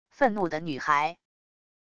愤怒的女孩wav音频